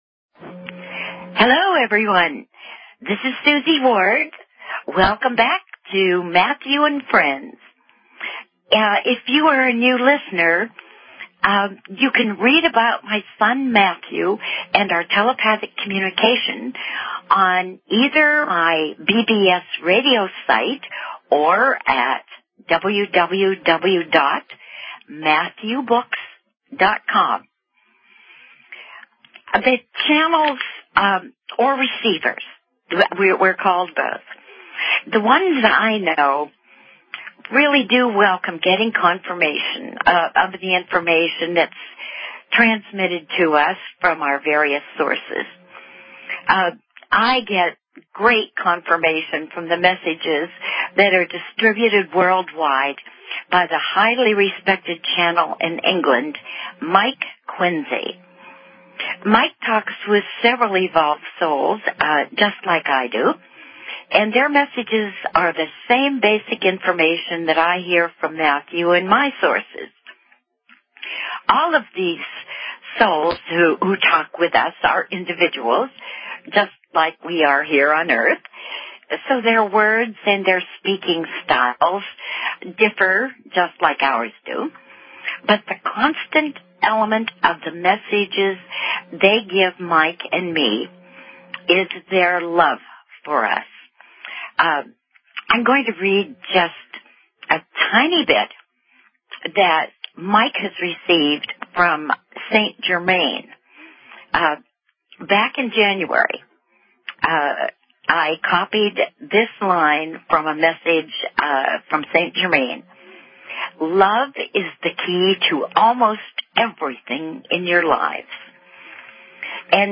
Talk Show Episode, Audio Podcast, Matthew_and_Friends_Hour and Courtesy of BBS Radio on , show guests , about , categorized as